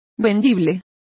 Complete with pronunciation of the translation of salable.